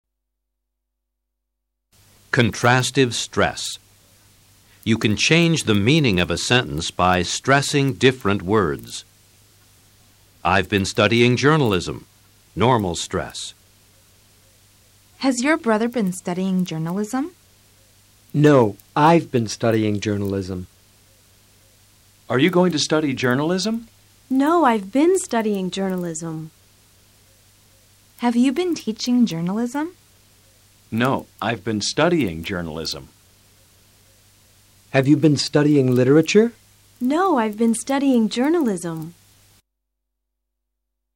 Contrastive Stress
Escucha a los profesores e intenta IMITAR SU FORMA DE ACENTUAR.